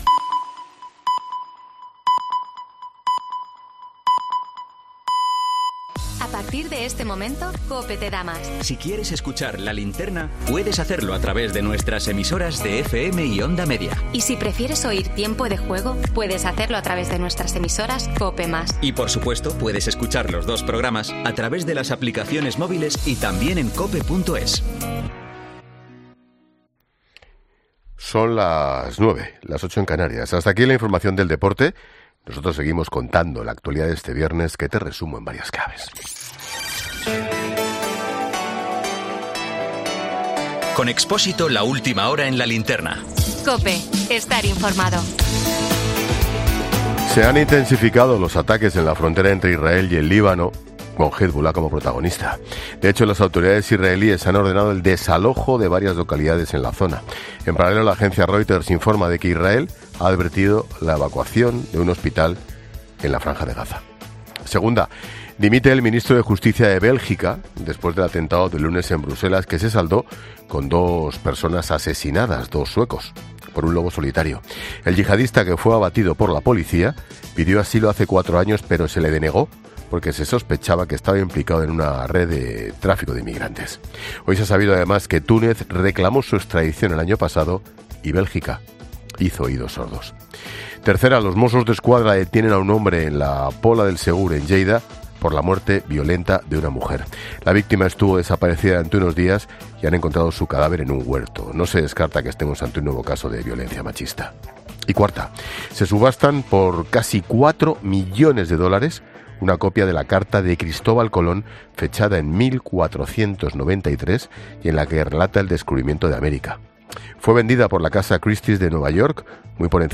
AUDIO: Boletín 21.00 horas del 20 de octubre de 2023 La Linterna